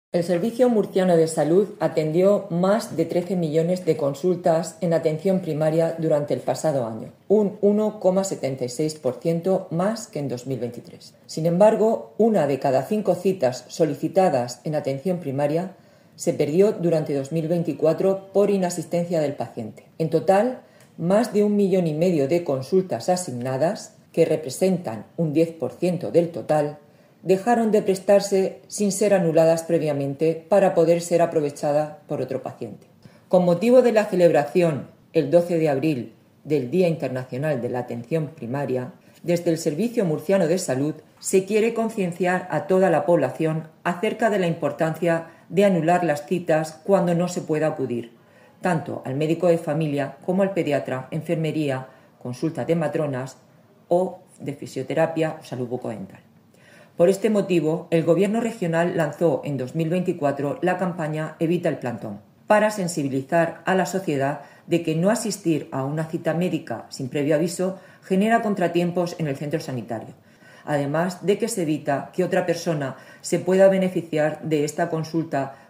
Sonido/ Declaraciones de la directora general de Atención Primaria, María José Marín, sobre las citas atendidas en 2024.